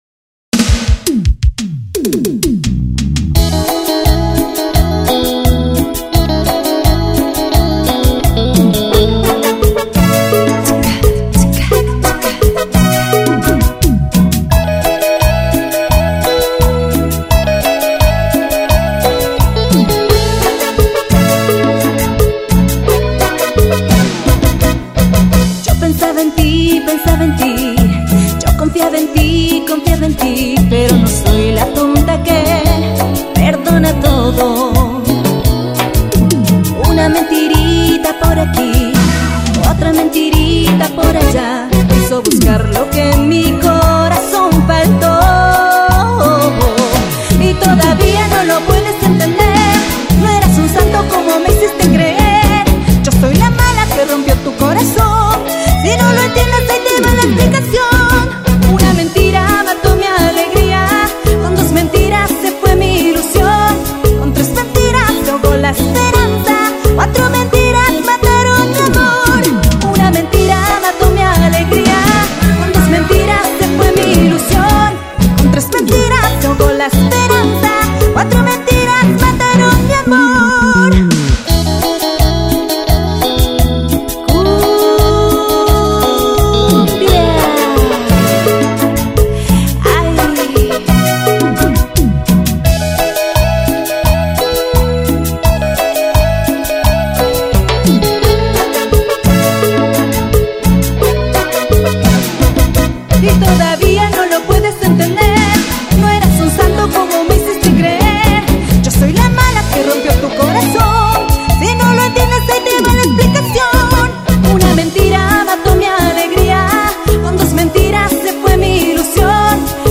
Llegó el momento de hacer CUMBIA !!